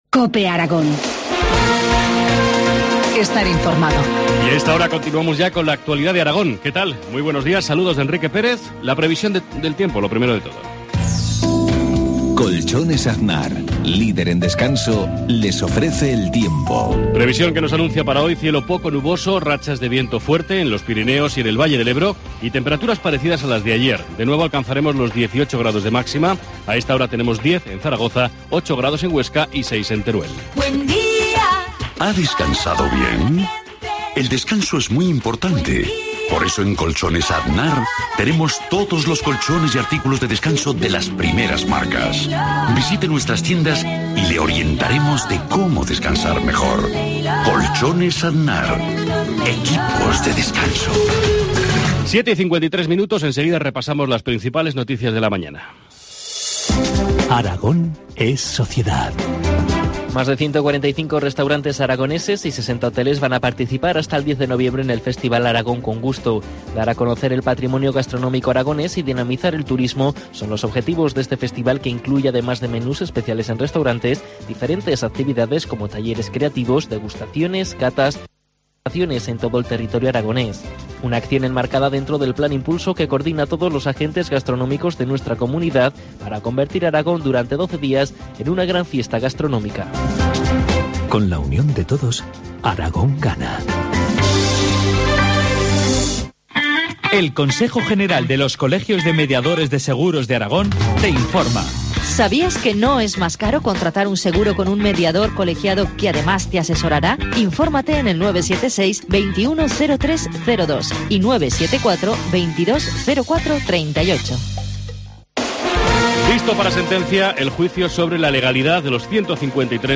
Informativo matinal, miércoles 30 de octubre, 7.53 horas